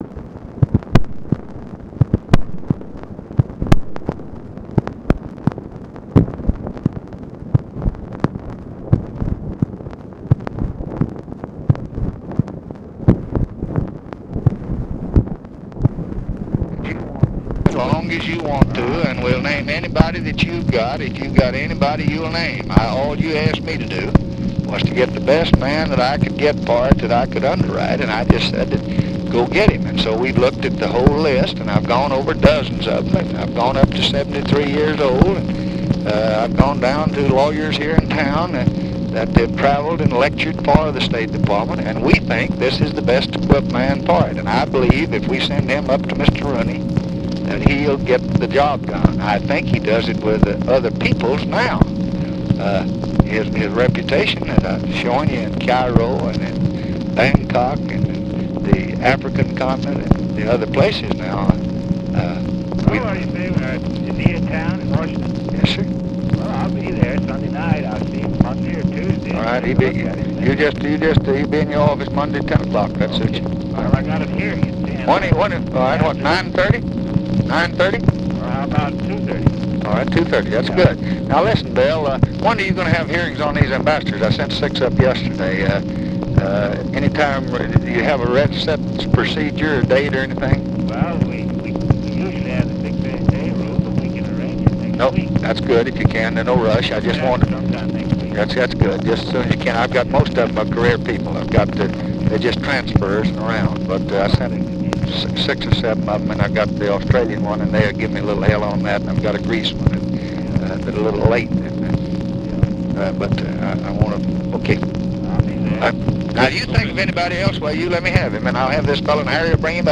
Conversation with WILLIAM FULBRIGHT and HARRY MCPHERSON, July 9, 1965
Secret White House Tapes